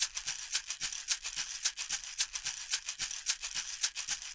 APH SHAKER-L.wav